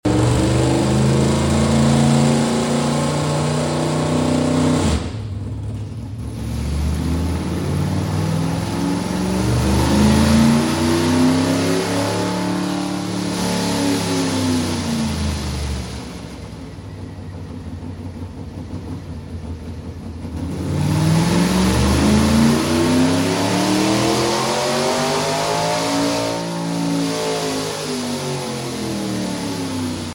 Little Raw Sound Dyno Session Sound Effects Free Download